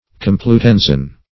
Search Result for " complutensian" : The Collaborative International Dictionary of English v.0.48: Complutensian \Com`plu*ten"sian\, a. Of or pertaining to Complutum (now Alcala de Henares) a city near Madrid; as, the Complutensian Bible.